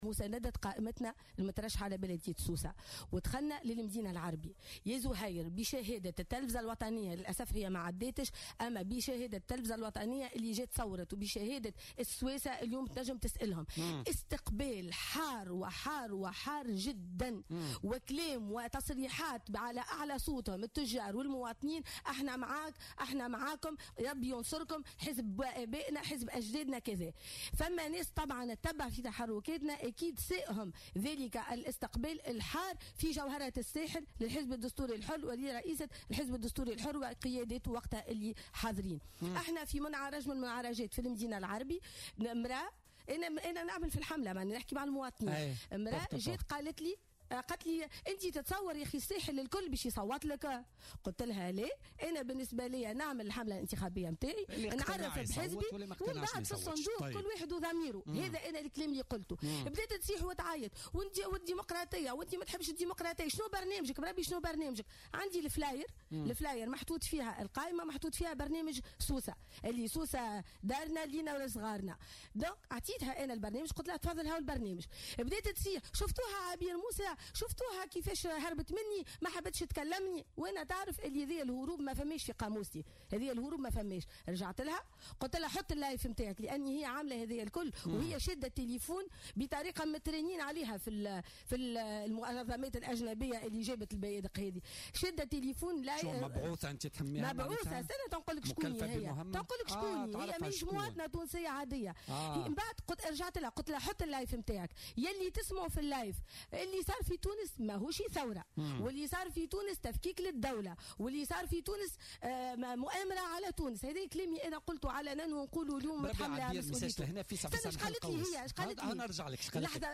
كشفت رئيسة الحزب الدستوري الحر عبير موسيضيفة بولتيكا اليوم الإثنين 14 ماي 2018 أن الحزب حظي باستقبال حار من قبل كافة أهالي المدينة العربي بسوسة والتجار المنتصبين هناك خلال حملتهم الانتخابية لكن هناك من ساءهم ذلك الاستقبال والمساندة وسعوا لإفساده.